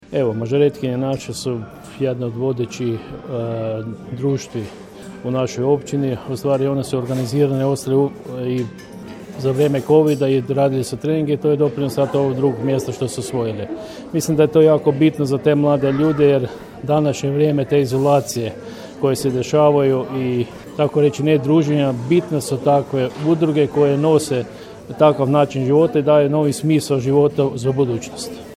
Državne prvakinje i doprvakinje, ali i europske viceprvakinje na prijemu kod načelnika
Uz cvijeće, na ovogodišnjim uspjesima čestitali su im načelnik Horvat i općinski vijećnik Vladimir Sabol.